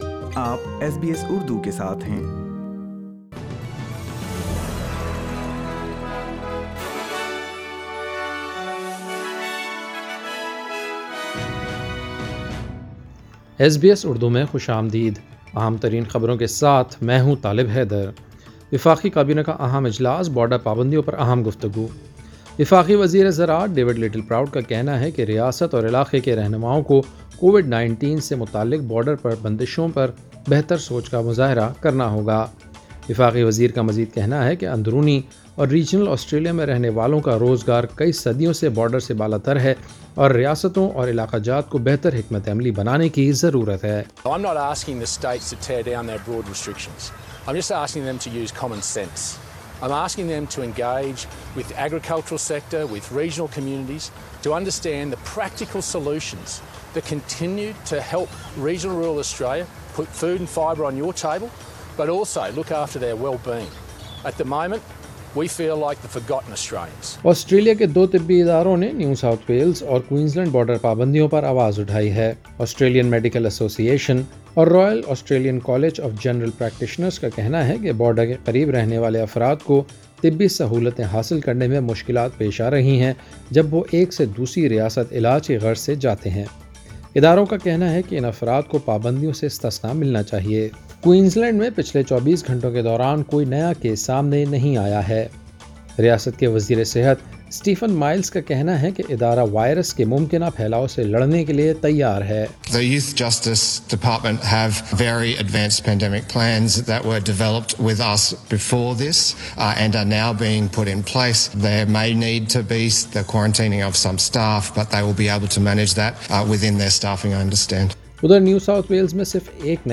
daily_news_21.8.20.mp3